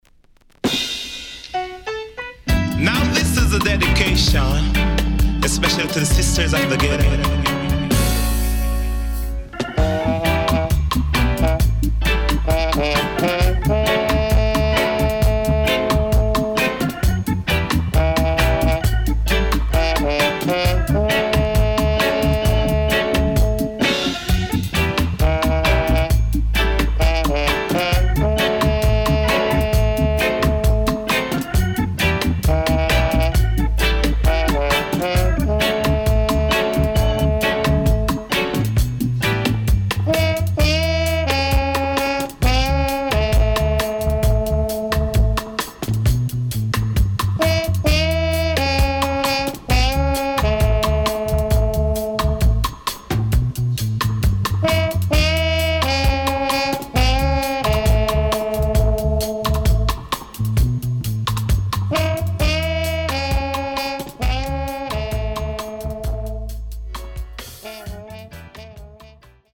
【12inch】